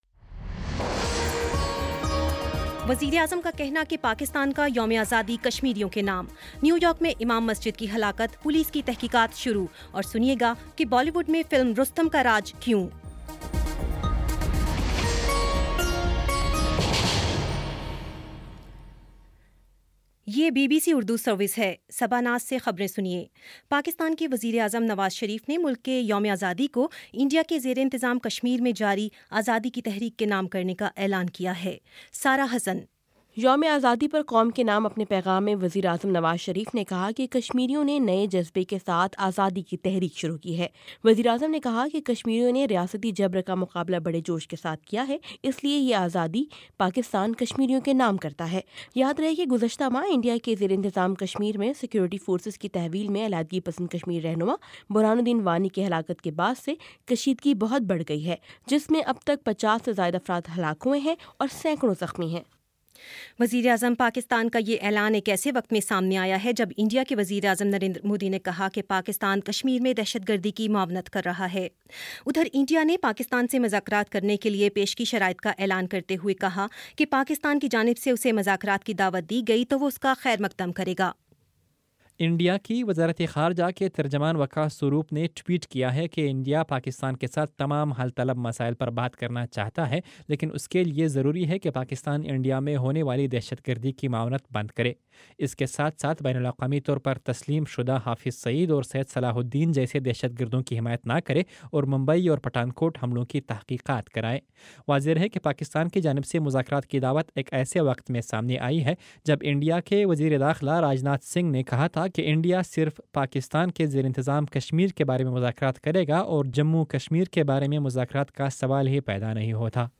اگست 14 : شام چھ بجے کا نیوز بُلیٹن